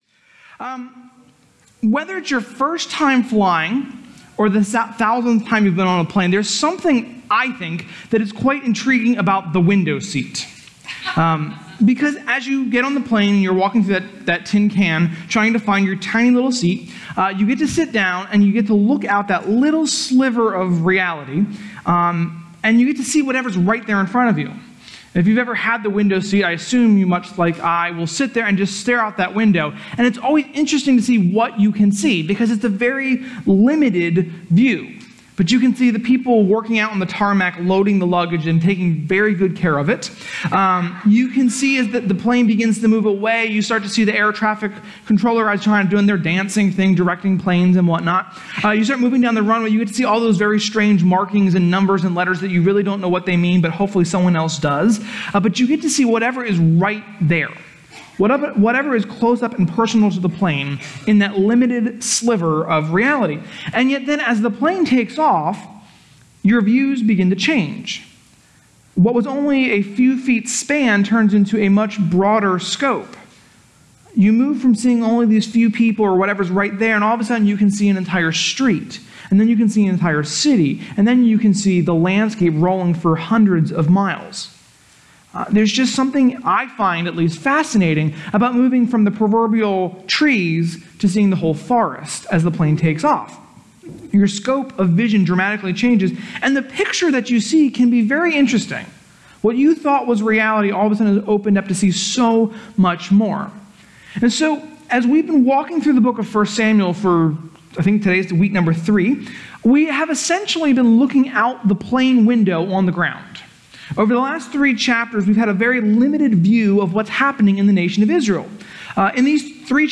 6.9.19-Sermon-mp3cut.net_.mp3